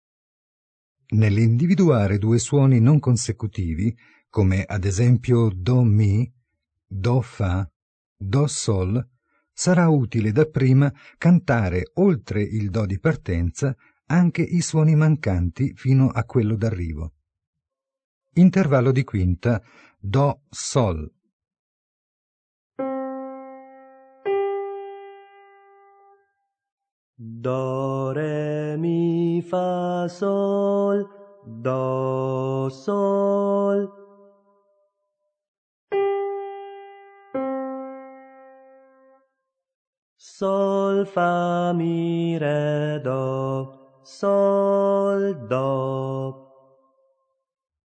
Ascoltate i consigli del Maestro e poi cantate insieme allo studente.